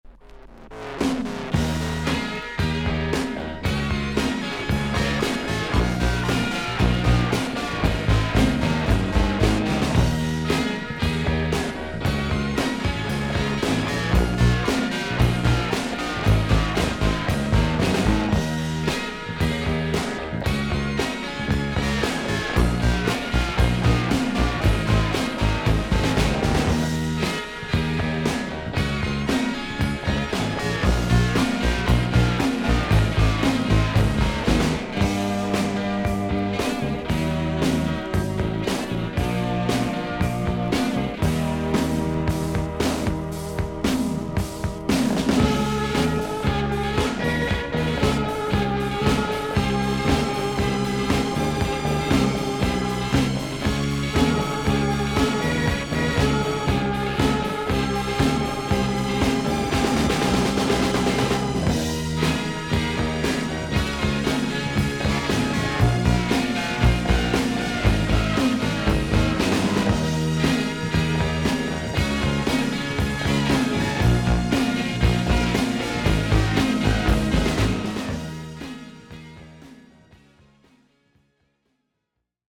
少々軽いパチノイズの箇所あり。クリアな音です。